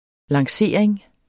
Udtale [ lɑŋˈseˀɐ̯eŋ ]